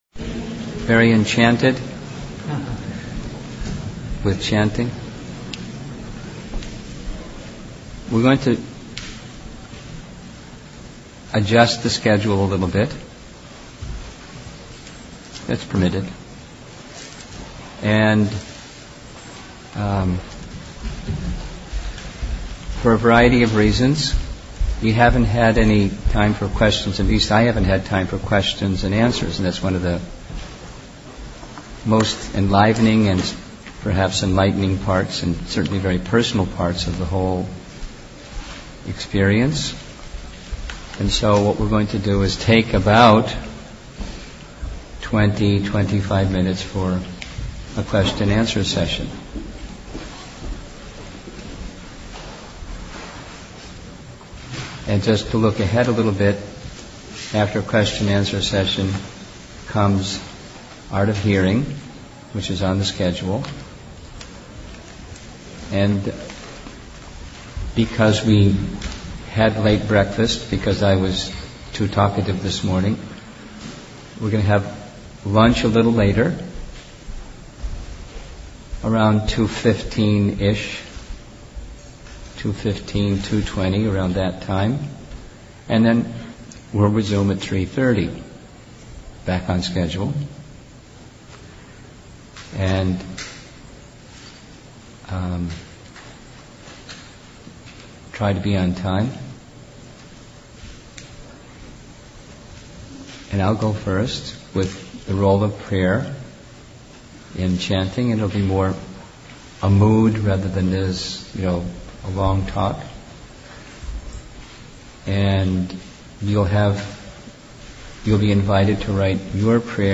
REC16–Questions and Answers